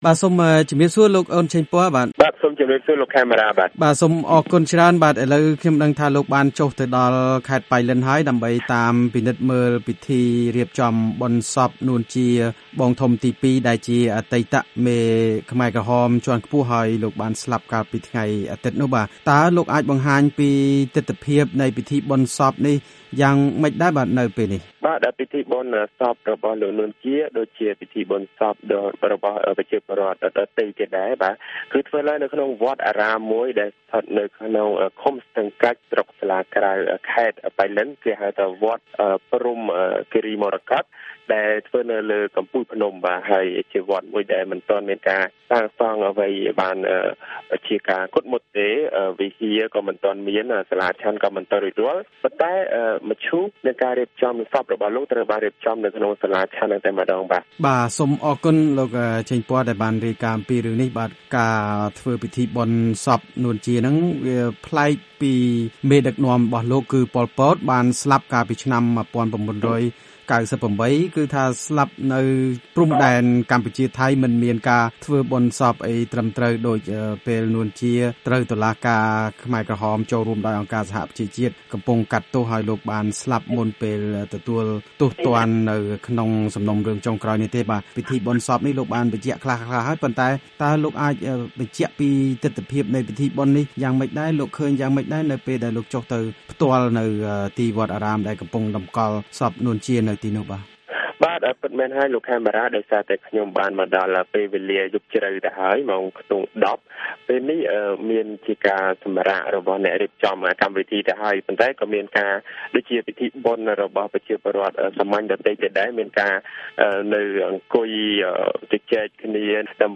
កិច្ចសន្ទនា VOA៖ ពិធីបុណ្យសពរបស់ នួន ជា នៅខេត្ត ប៉ៃលិន
បទសម្ភាសន៍